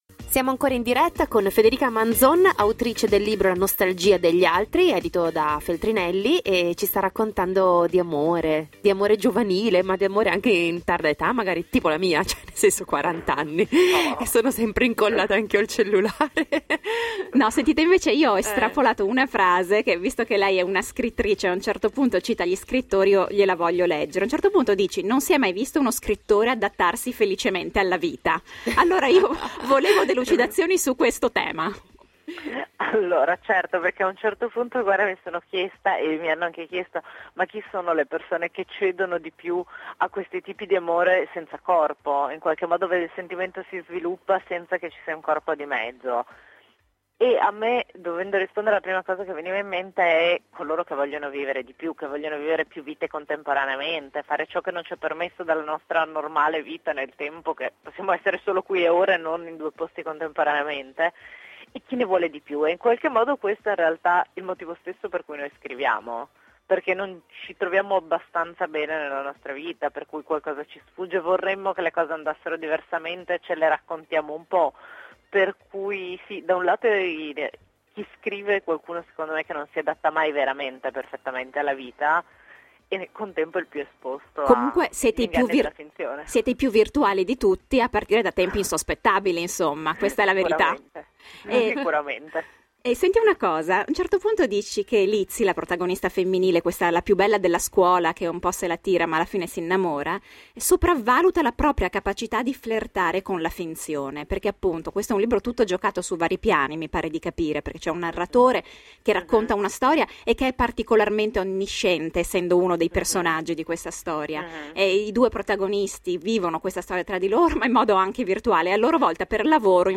I più forti e i più pericolosi si annidano dove meno ce l’aspettiamo. Ascolta la seconda parte dell’intervista (o scaricala qui):